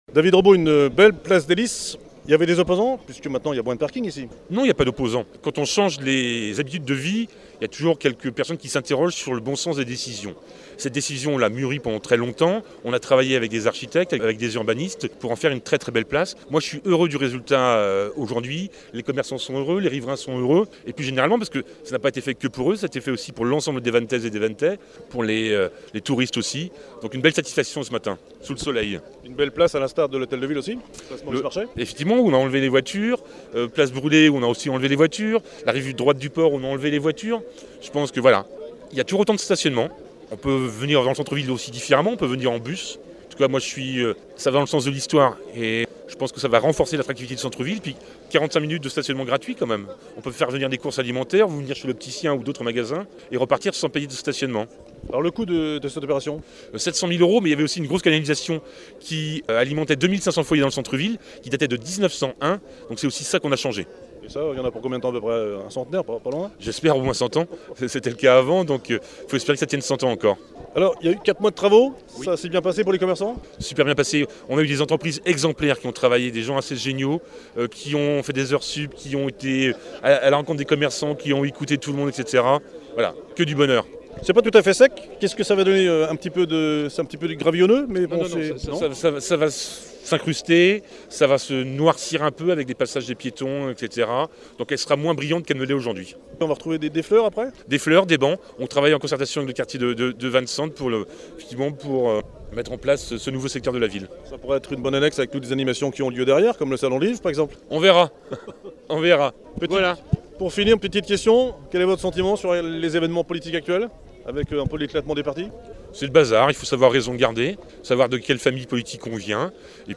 Interview de David Robo – Maire de Vannes (réalisée lors de l’inauguration en mai 2017)
place-des-lices-david-robo-et-politique-juin-2017-rk-m.mp3